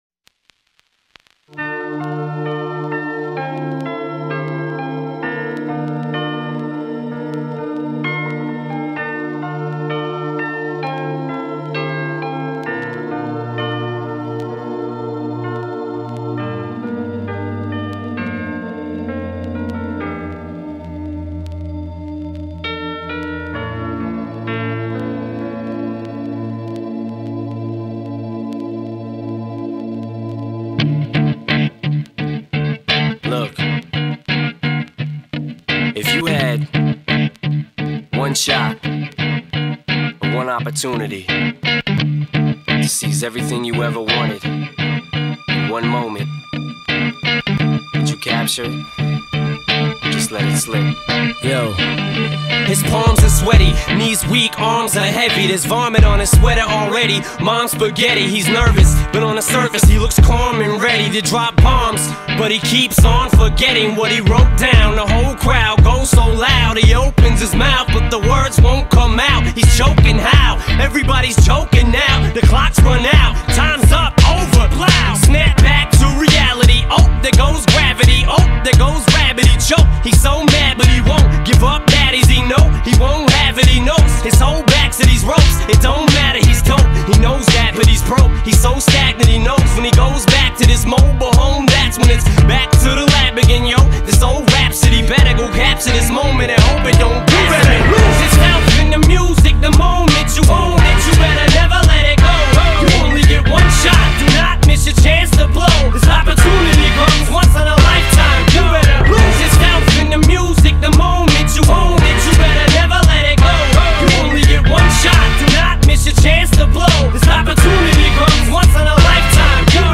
آهنگ انگیزشی انگلیسی
آهنگ انگیزشی رپ خارجی